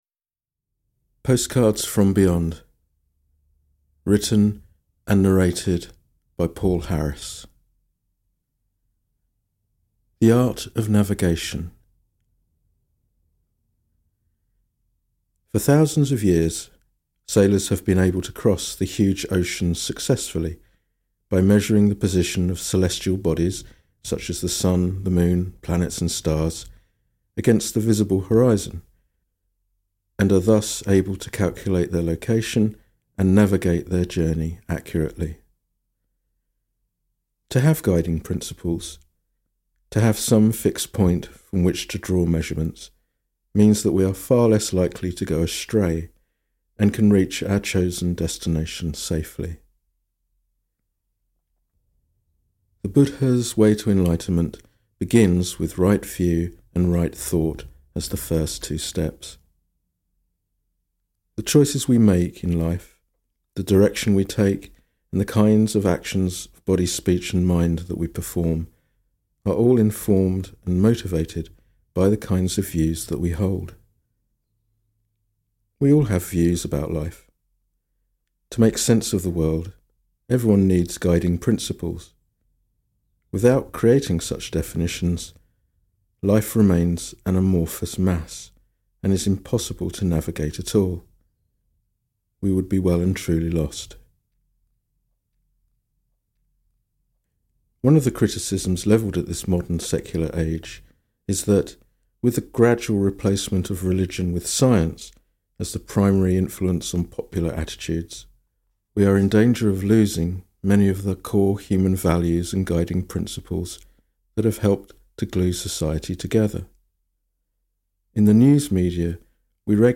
Audio recording of the book